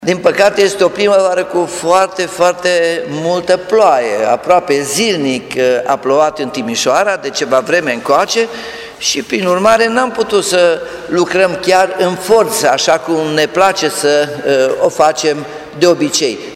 Primarul Nicolae Robu a anunțat că până acum nu s-a putut lucra din cauza vremii, dar de îndată de vremea permite, străzile din partea de sud și partea de nord a orașului, vor fi asfaltate: